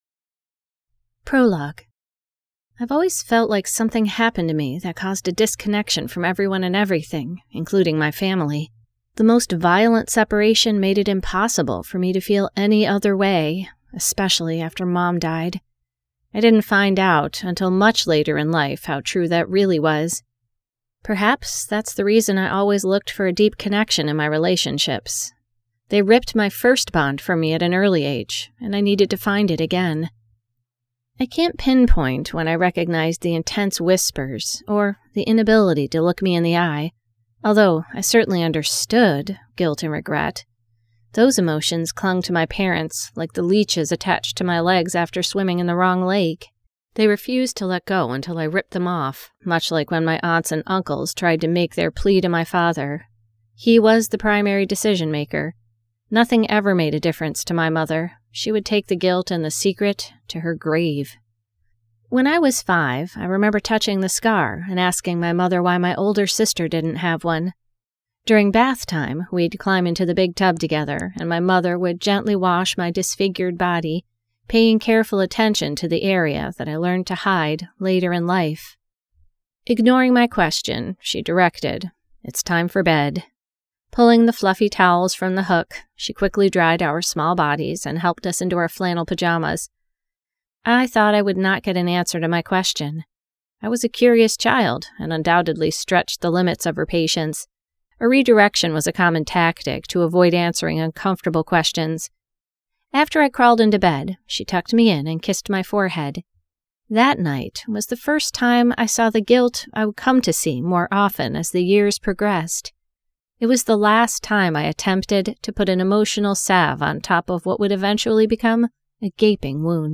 Disconnected by Annette Mori [Audiobook]